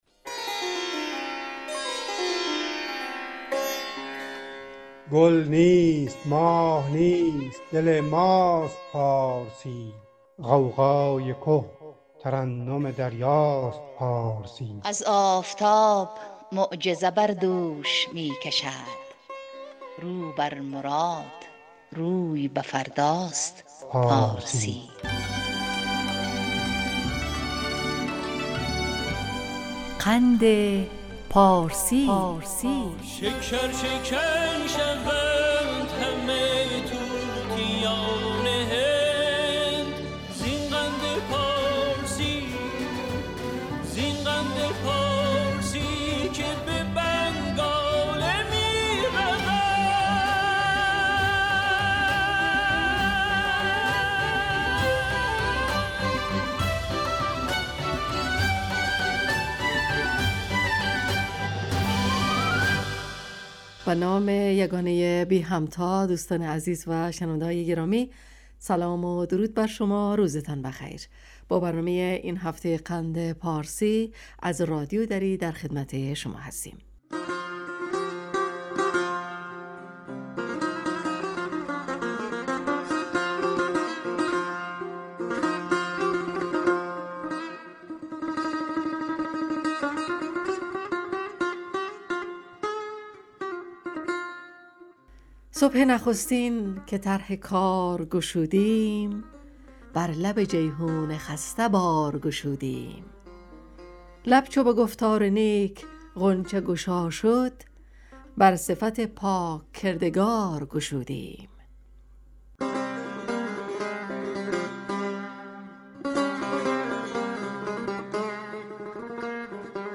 برنامه ادبی رادیو دری